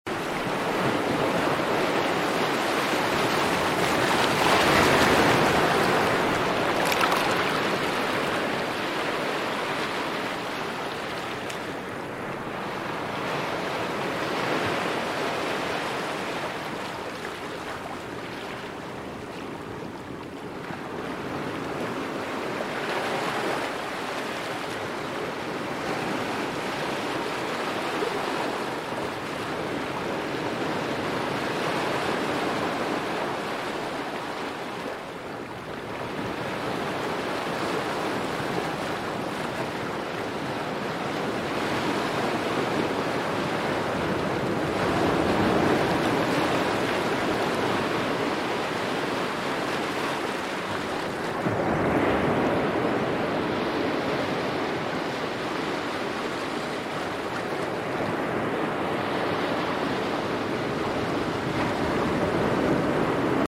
calming sounds 💙 relax in just 1 minute